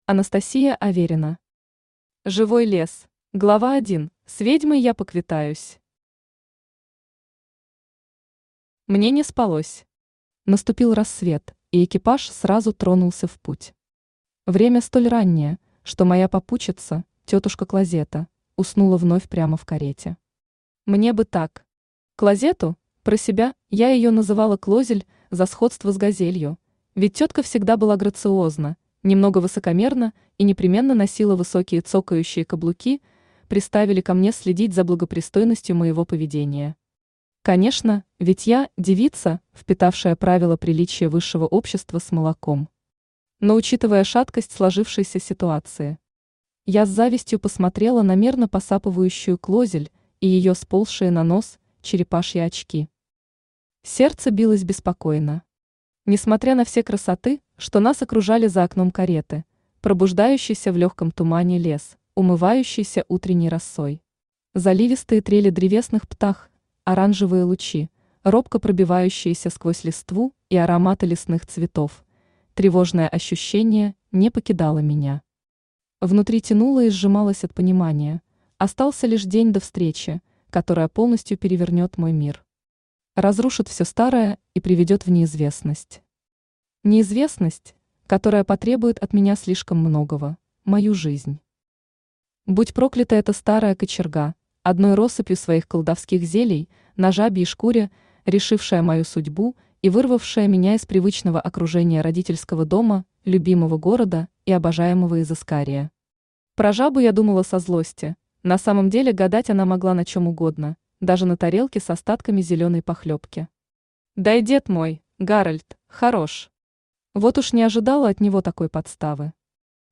Aудиокнига Живой лес Автор Анастасия Аверина Читает аудиокнигу Авточтец ЛитРес. Прослушать и бесплатно скачать фрагмент аудиокниги